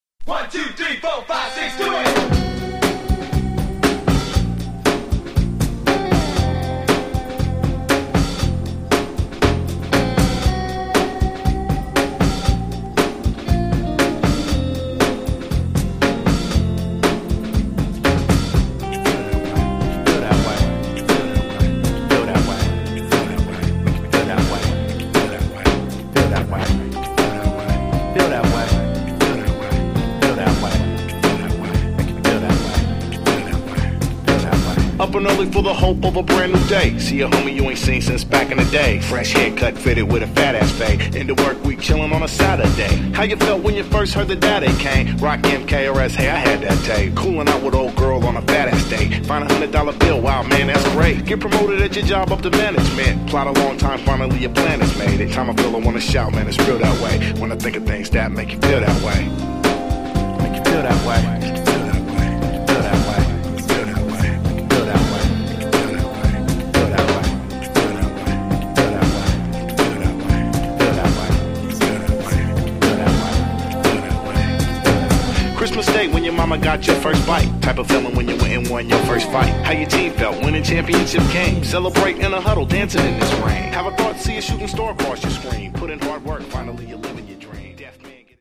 119 bpm Clean Version Duration